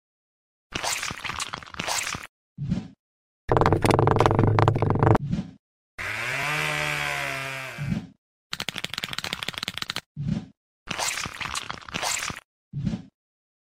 Sergio Ramos ASMR | Football Legend Whispers